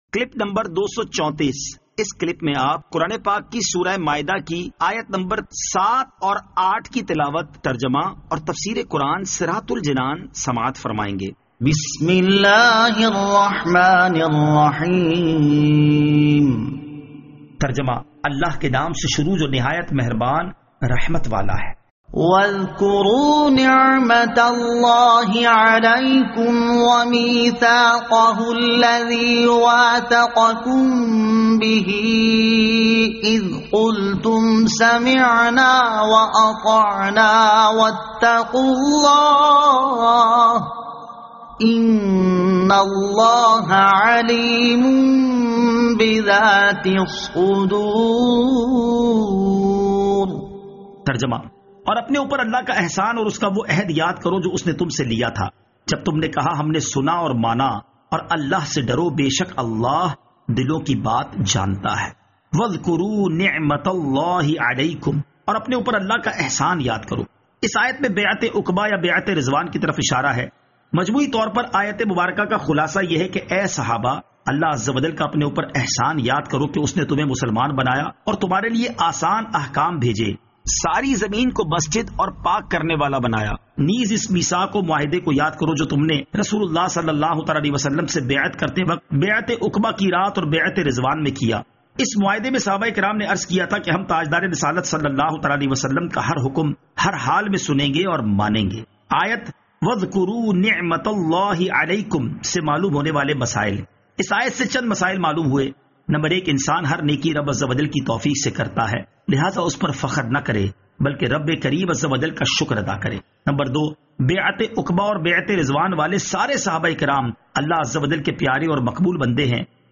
Surah Al-Maidah Ayat 07 To 08 Tilawat , Tarjama , Tafseer
2020 MP3 MP4 MP4 Share سُوَّرۃُ ٱلْمَائِدَة آیت 07 تا 08 تلاوت ، ترجمہ ، تفسیر ۔